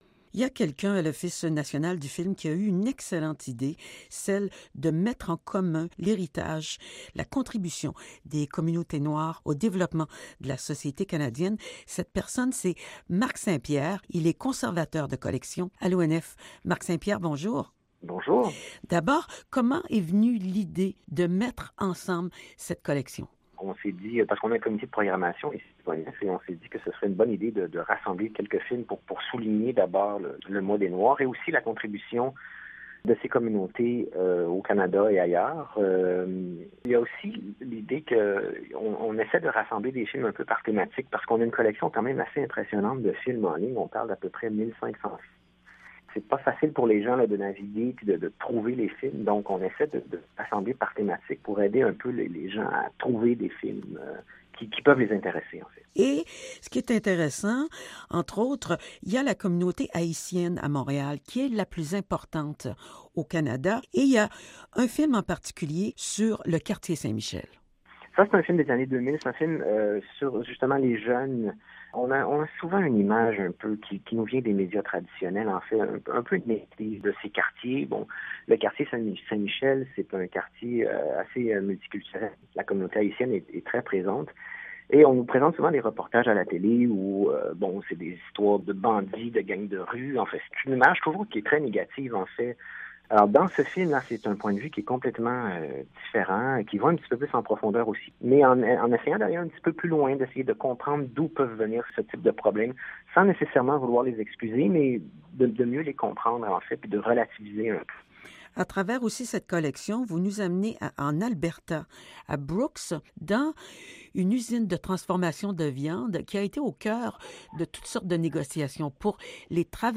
6 février – RADIO CANADA INTERNATIONAL – La collection de films et de documentaires que possède l’Office national du film du Canada est gigantesque.
Interviewmhn.mp3